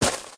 Divergent / mods / Footsies / gamedata / sounds / material / human / step / t_gravel3.ogg
t_gravel3.ogg